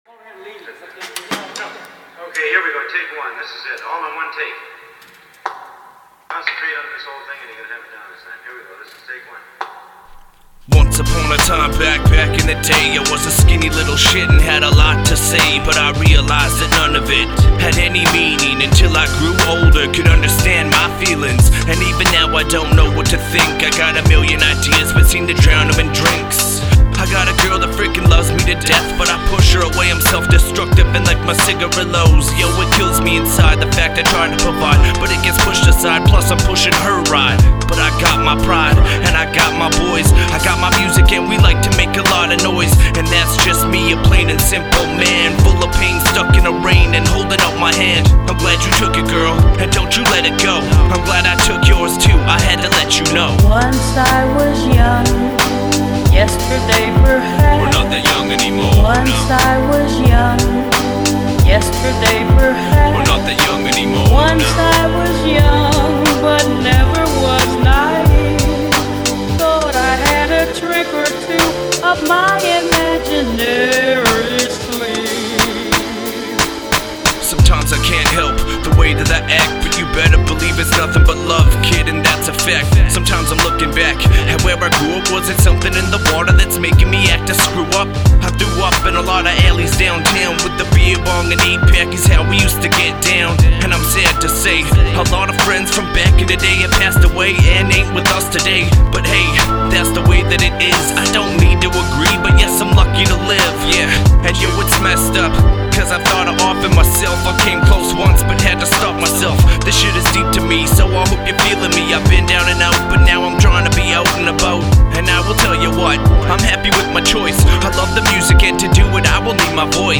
energetic tracks with dark undertones
real hip hop from the heart
Recorded at Ground Zero Studios